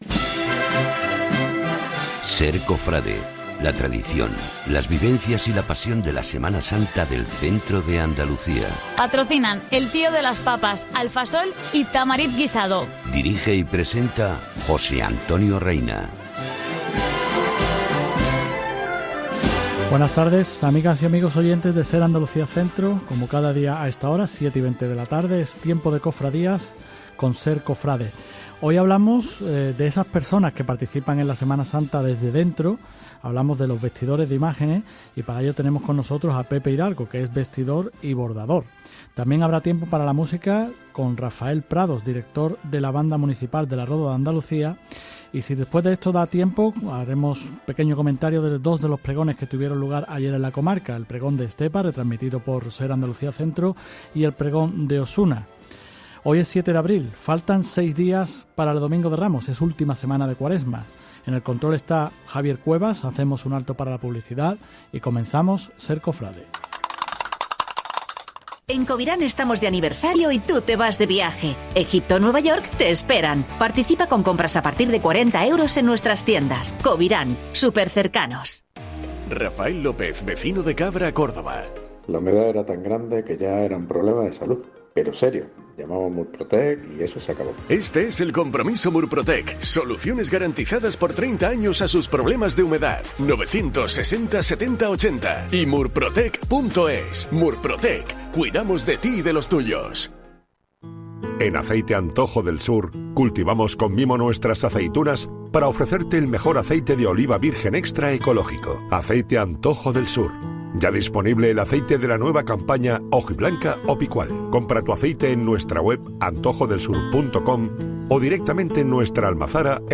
SER Cofrade Andalucía Centro - 7 de abril de 2025 Programa SER Cofrade emitido el lunes 7 de abril de 2025. Entrevista